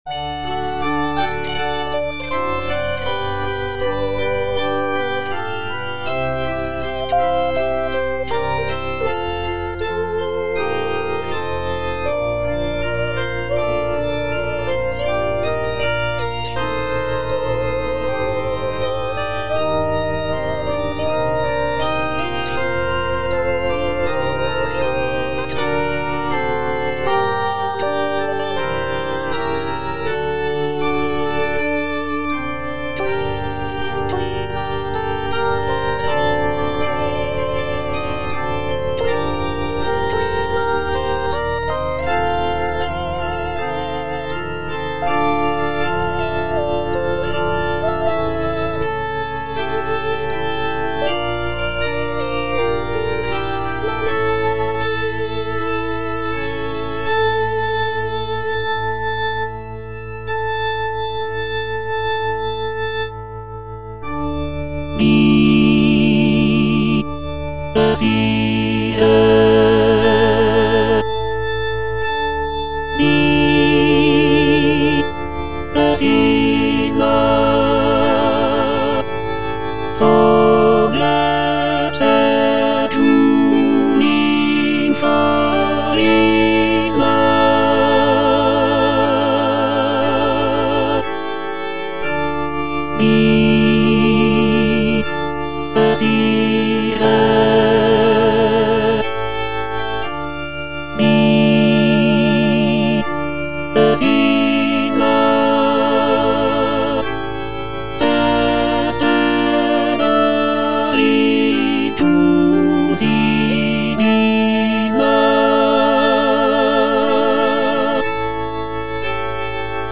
Tenor (Emphasised voice and other voices) Ads stop